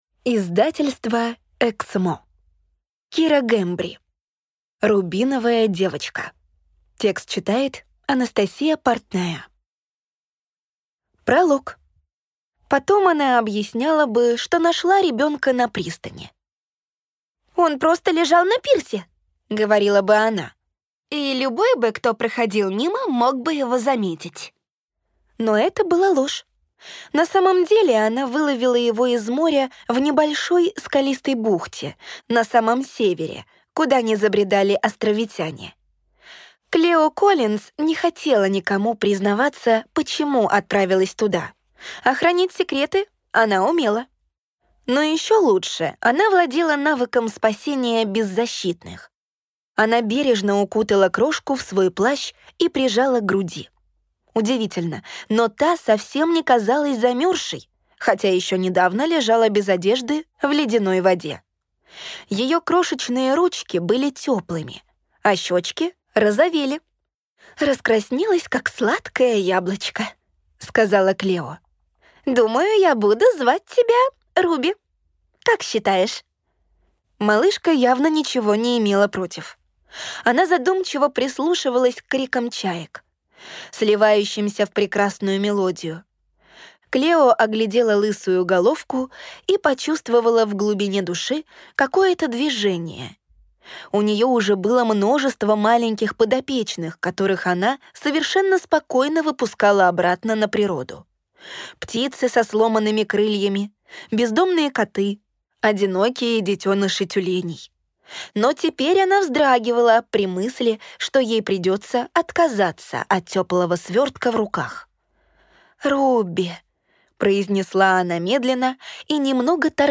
Аудиокнига Рубиновая девочка | Библиотека аудиокниг